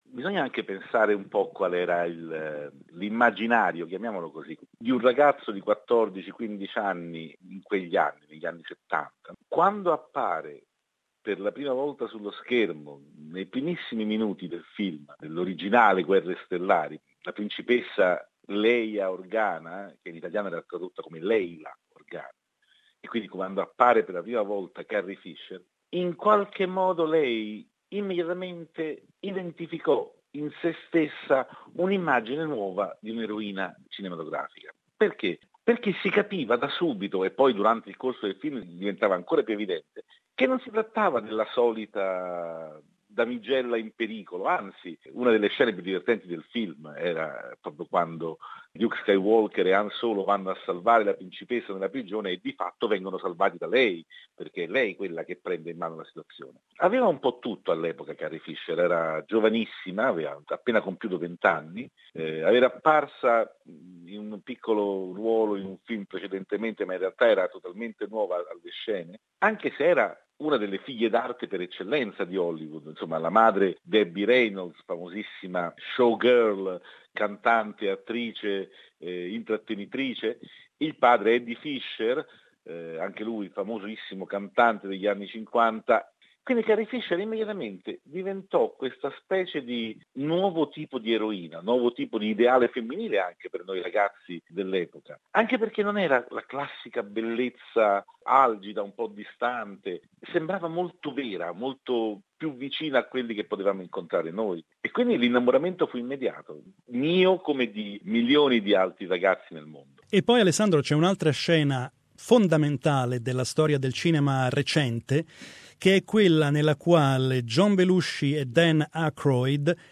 We spoke with film critic